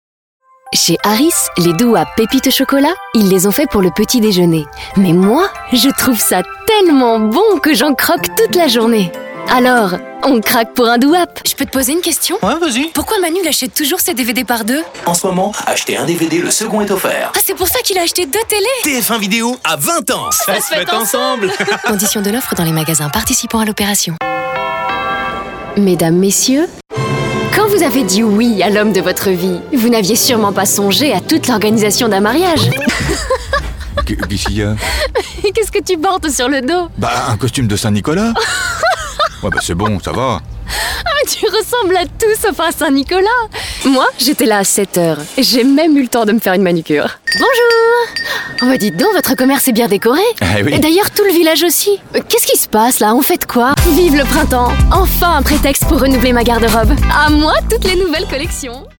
Démo "Jouer" Pub Comédie